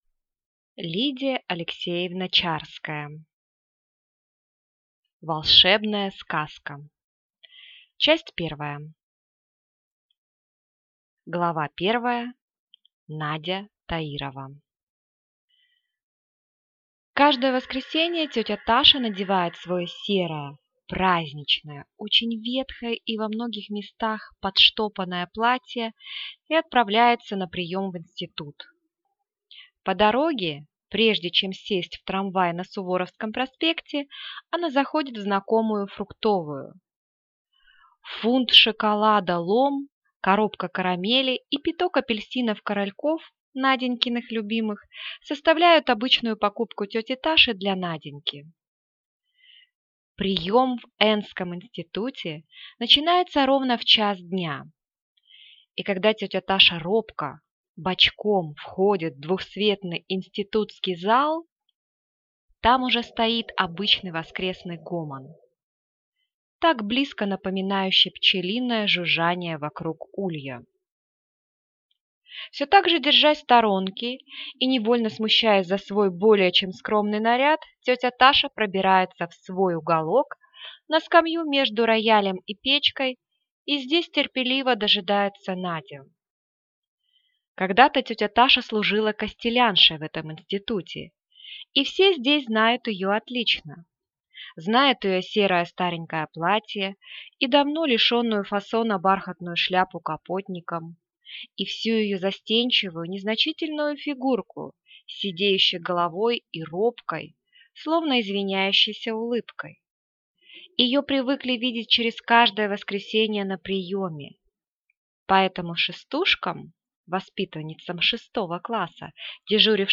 Аудиокнига Волшебная сказка | Библиотека аудиокниг